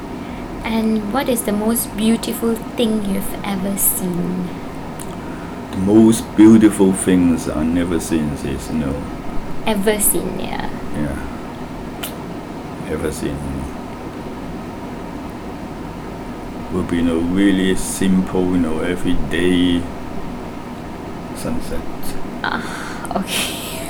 S1 = Hong Kong male S2 = Malaysian female
There are three possibilities: first, S1 misheard the question, and thought it was about what he had never seen; second, S1 got his grammar confused and replied using never rather than ever ; and third, it is a pronunciation error, with a spurious [n] on the front of ever . The long pause in S1's final response suggests he may be trying to correct himself.